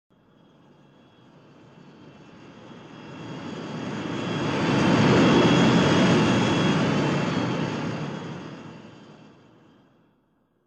BSG FX - Viper Group - Pass by, together
BSG_FX_-_Viper_Group_-_Pass_by2C_together.wav